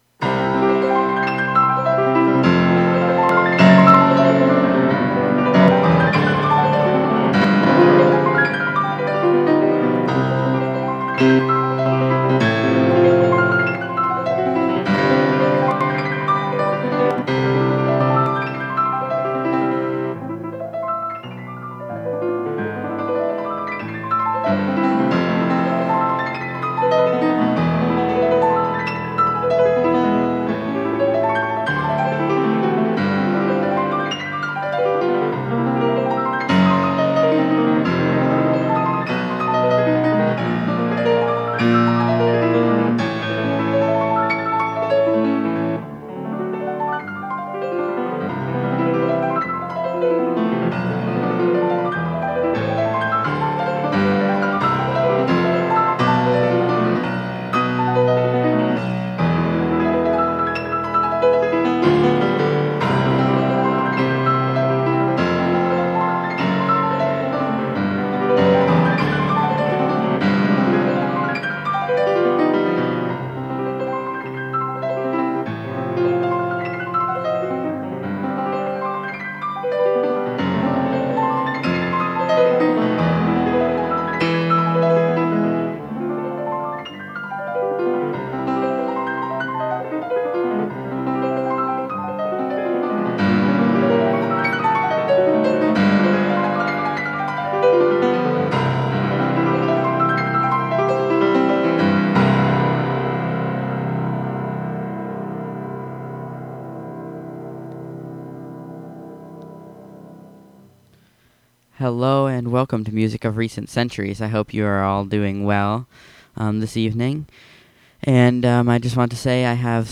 In this broadcast, the 19th-century compositions of Polish piano virtuoso Frédéric Chopin.